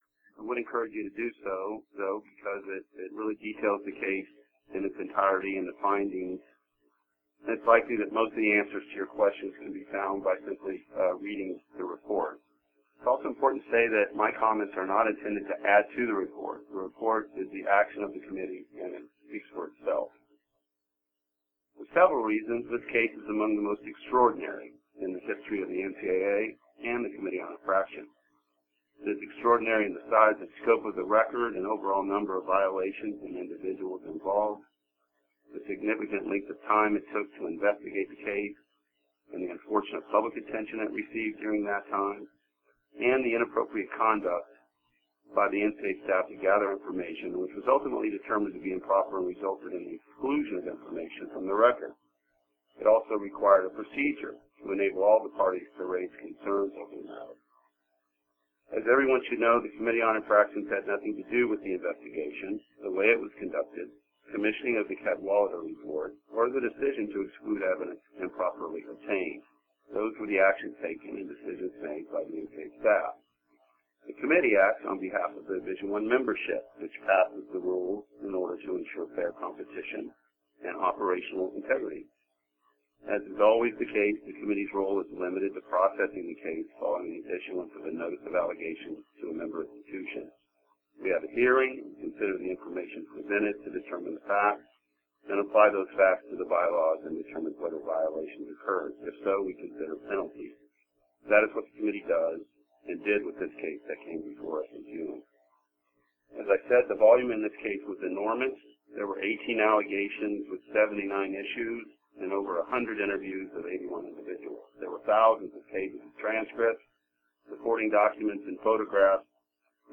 NCAA Division I Committee on Infractions Media Teleconference regarding the University of Miami (FL)